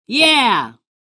Notification Sounds / Sound Effects
Yeah-sound.mp3